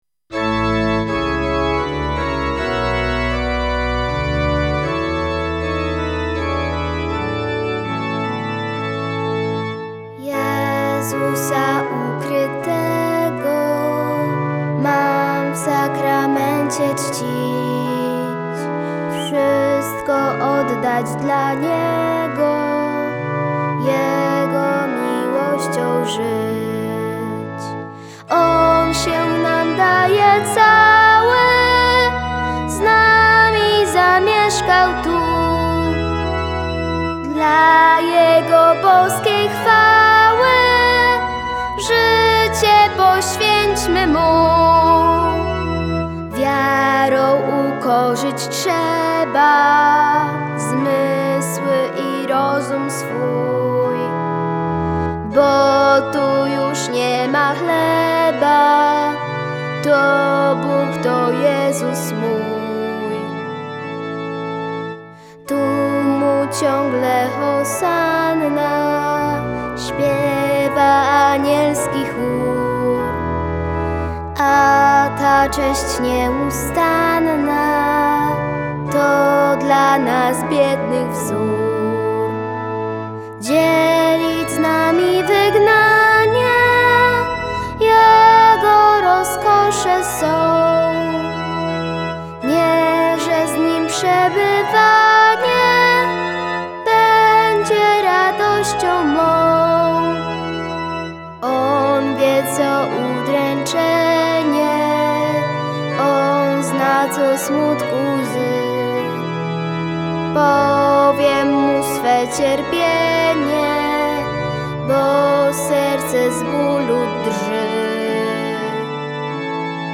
Utwór muzyczny